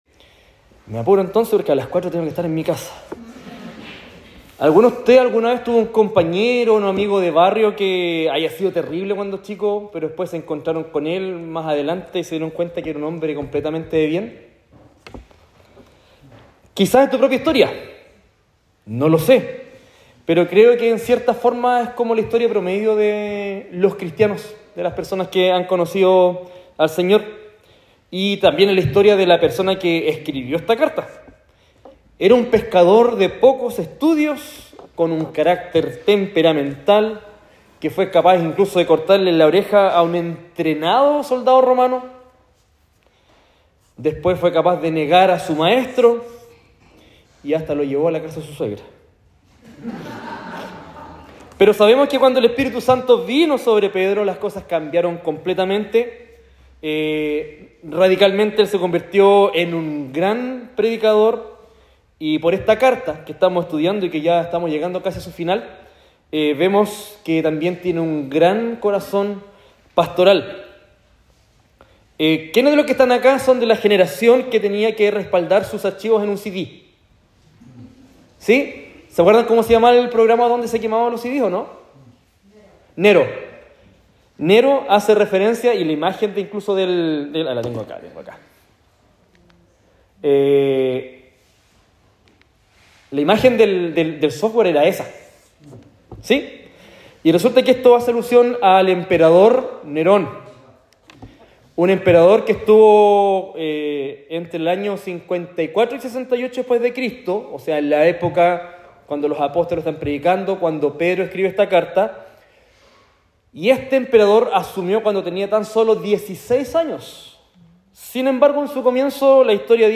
Sermón sobre 1 Pedro 4 : 1 - 19